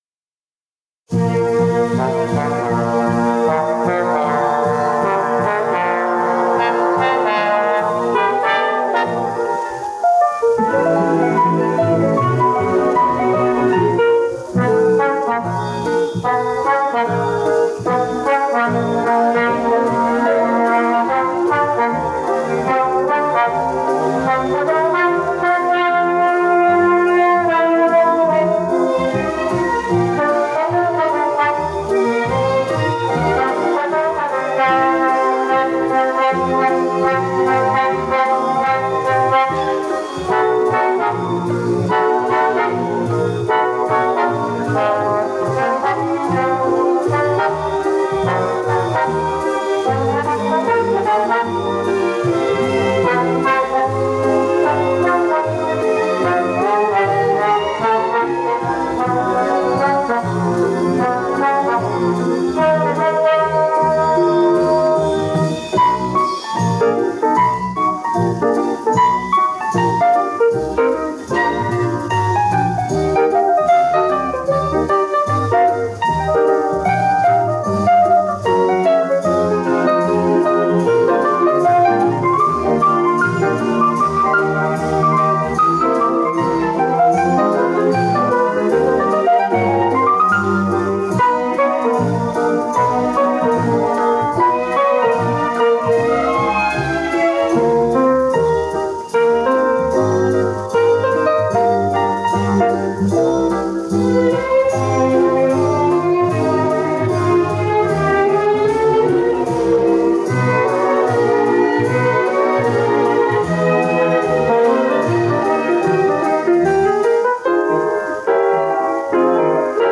Ещё одна версия шумоподавления